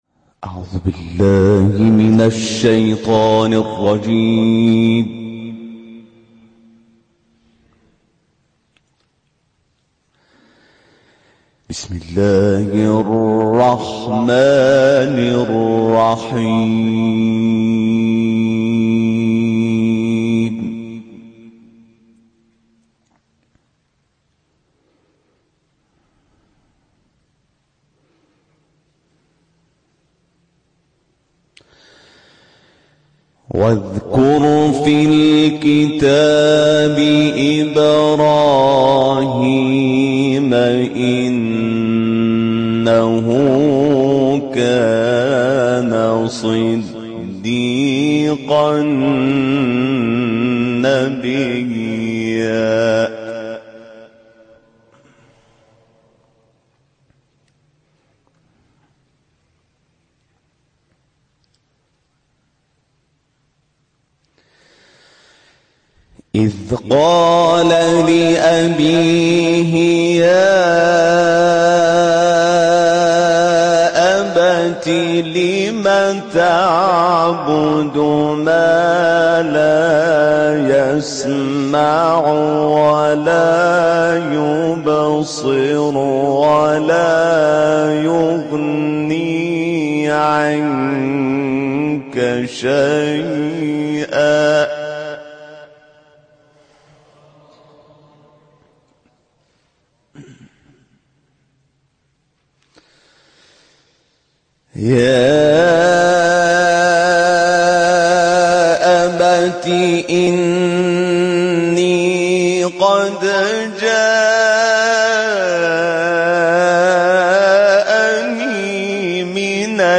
گروه چندرسانه‌ای ــ کاروان قرآنی انقلاب در هشتمین روز از سفر 22 روزه خود در پایگاه سوم دریایی سپاه بندر ماهشهر و مسجد شهداء این شهر، صوت دلنشین کلام الله مجید را جاری کردند.